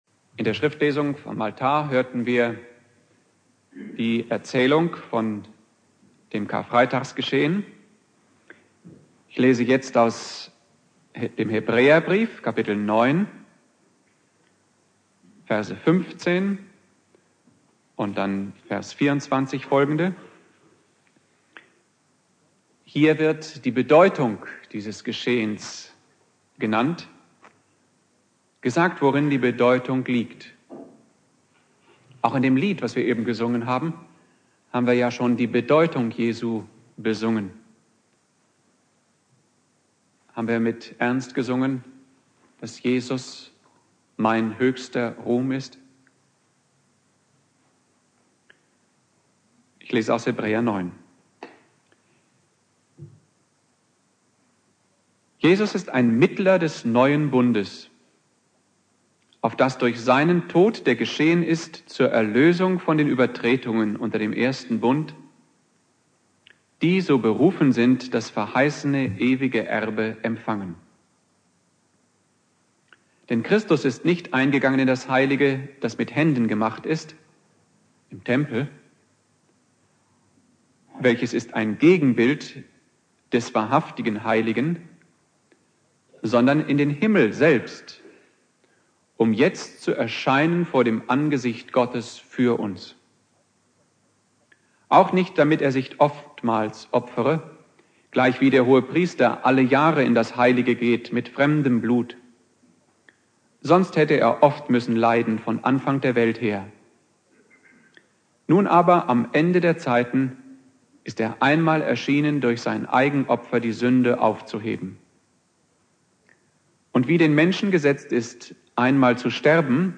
Predigt
Karfreitag Prediger